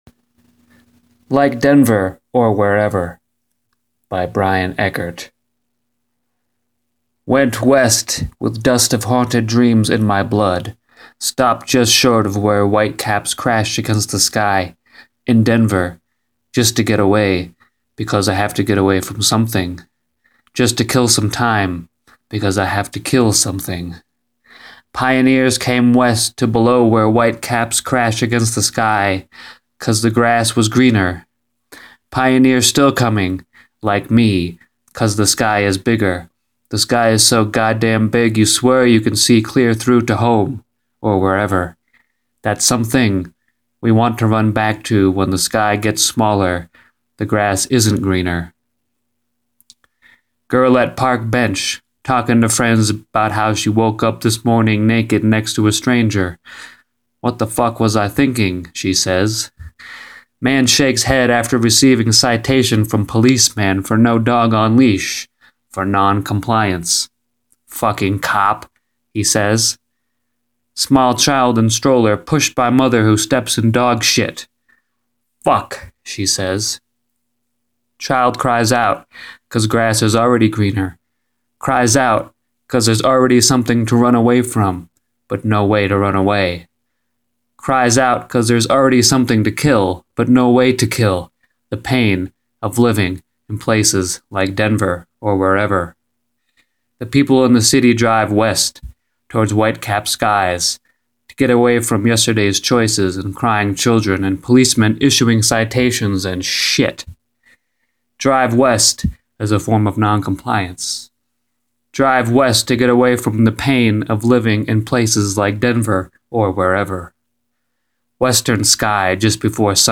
Spoken Word: Like Denver or wherever